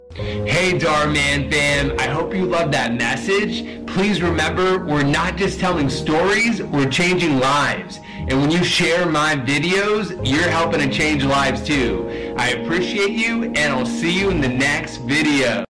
Hey Dharmann fam! (Ear damage)
hey-dharmann-fam-ear-damage.mp3